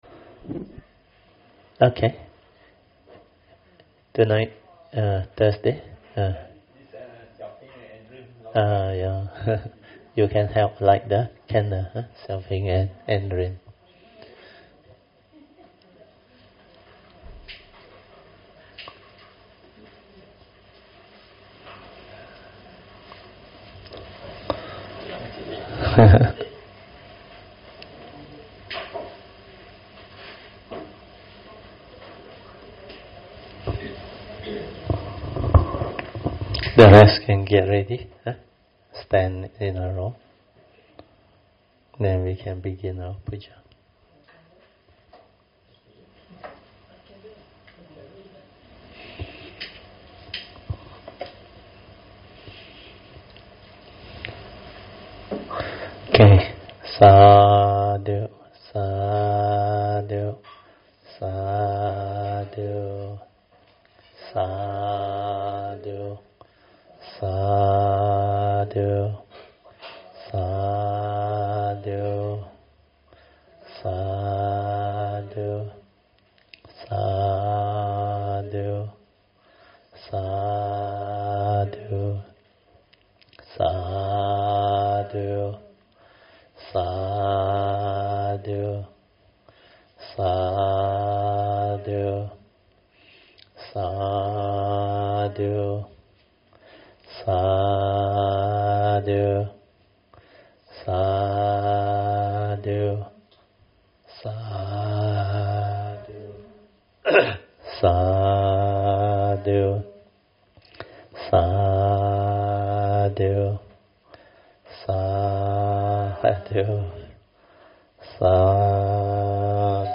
Thursday Class